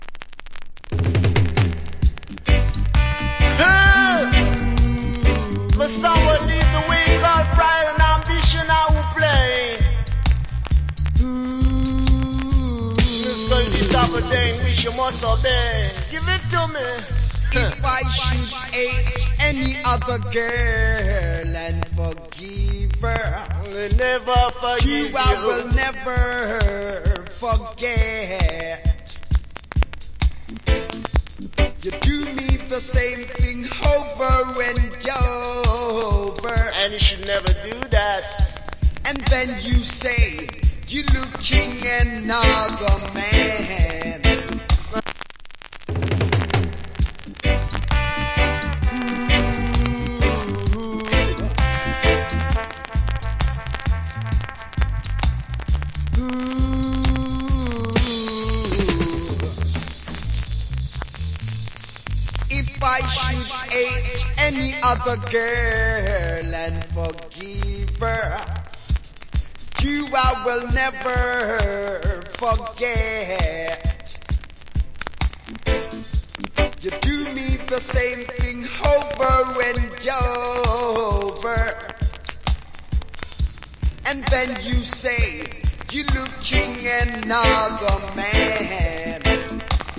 category Reggae
Nice Dub.